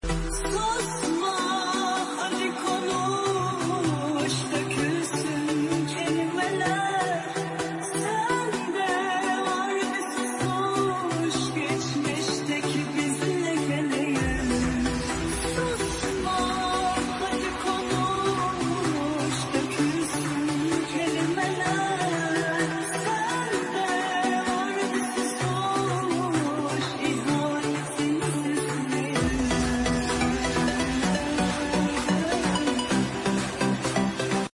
owl-flap-sound-CpkkFU9L.mp3